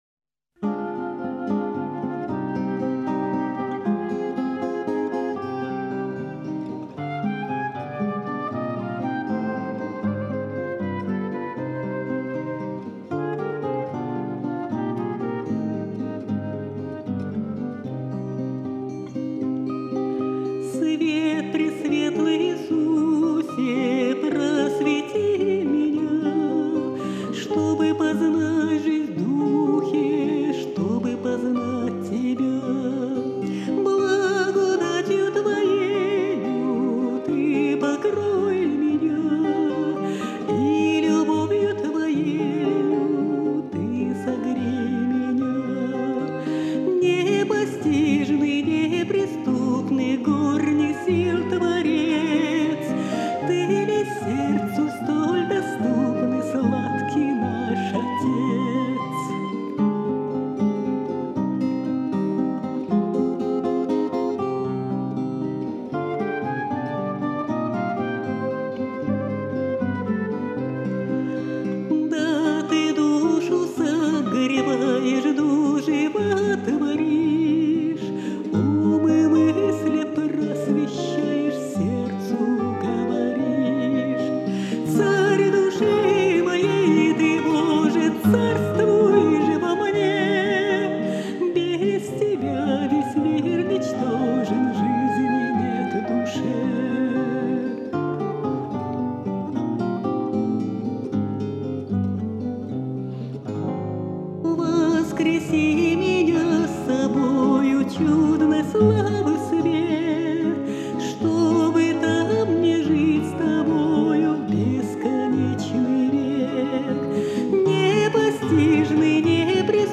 Духовная музыка
Она обладает глубоким лирико - драматическим меццо-сопрано.